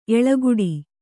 ♪ eḷaguḍi